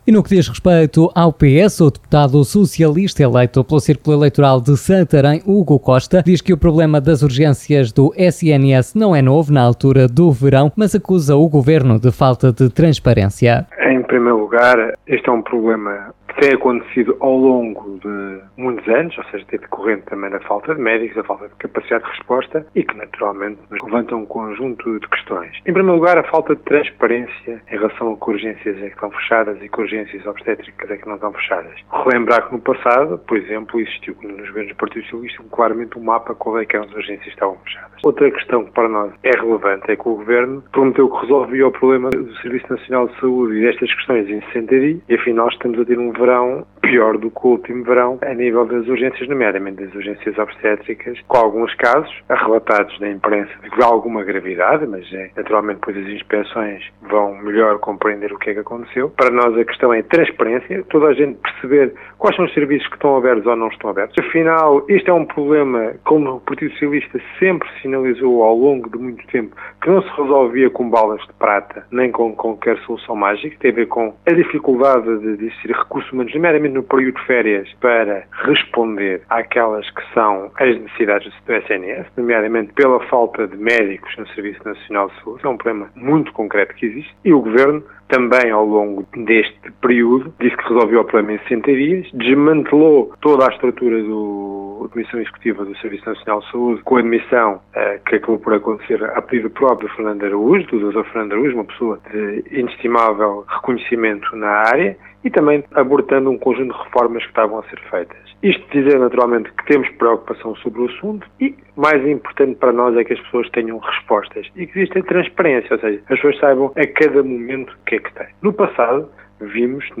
Foram convidados deste Especial Informação o Deputado eleito pelo CHEGA, Pedro Frazão, Hugo Costa, do Partido Socialista e Inês Barroso, Deputada eleita pelo Partido Social Democrata.